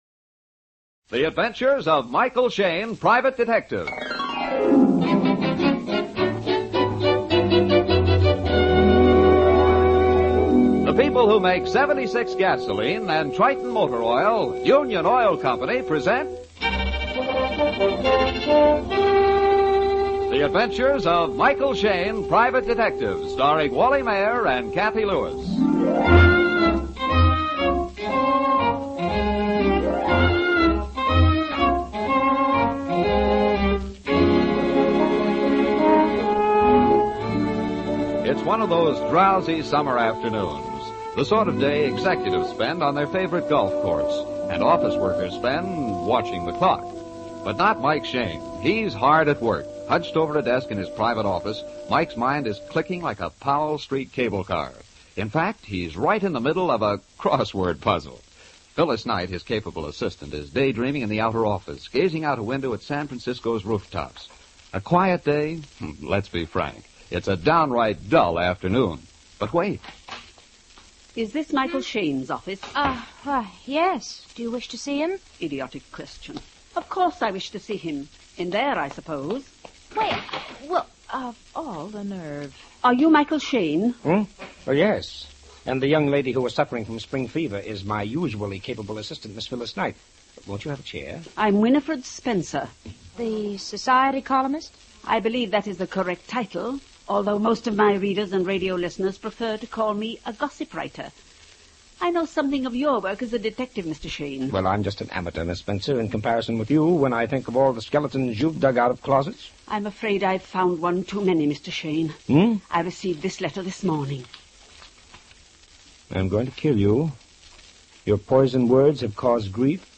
Michael Shayne 450709 Gossip Writer, Old Time Radio